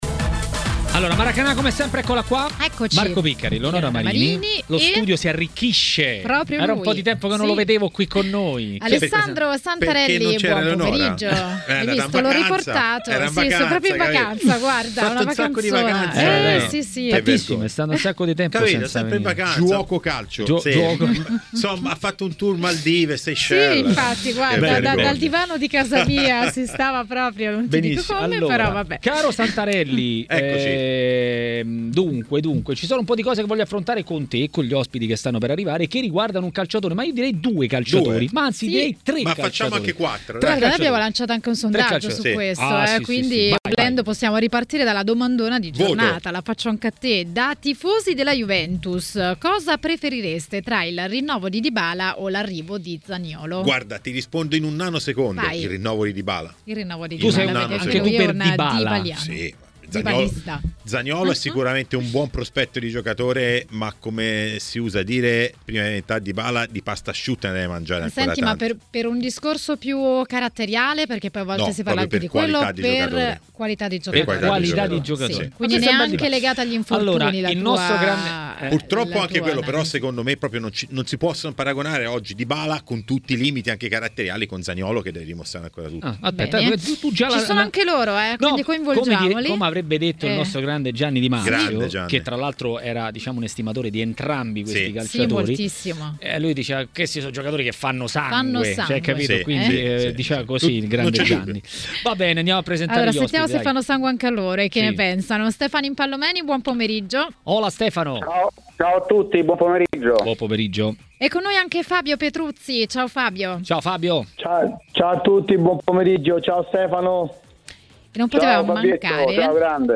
L'ex difensore Fabio Petruzzi è intervenuto a TMW Radio, durante Maracanà, per parlare dei temi del giorno.